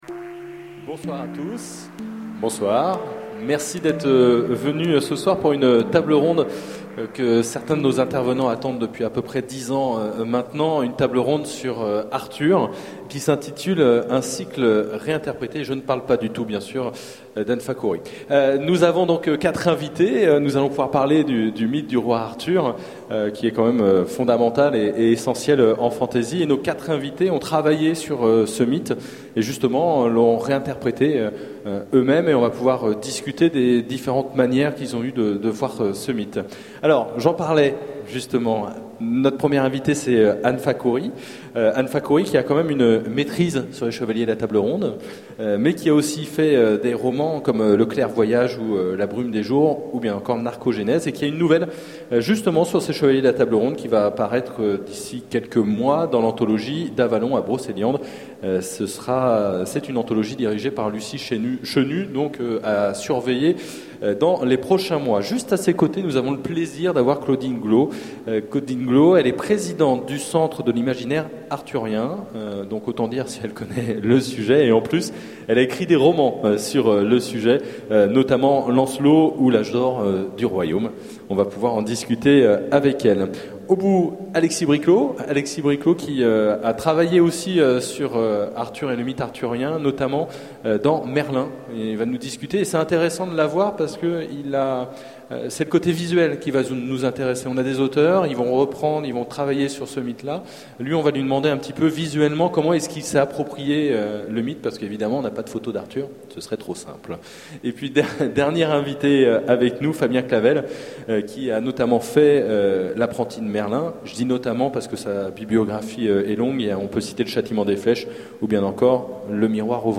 Utopiales 2011 : Conférence Arthur, un cycle réinterprété ?